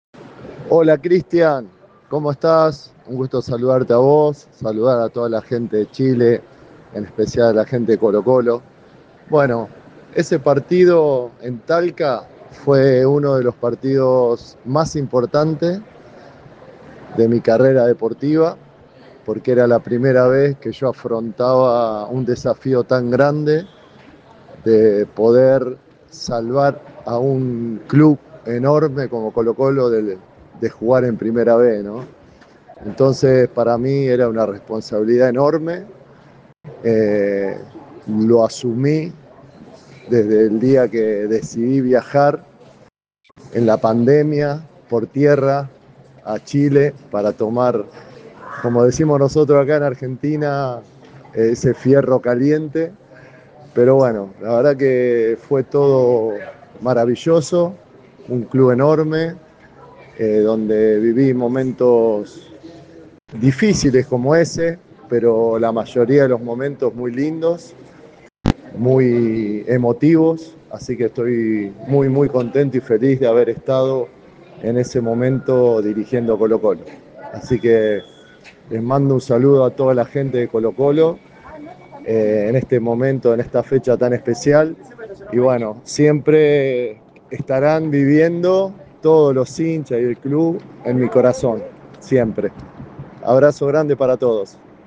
En conversación exclusiva con ADN Deportes, el actual DT de Gremio, conmemoró aquel 17 de febrero de 2021.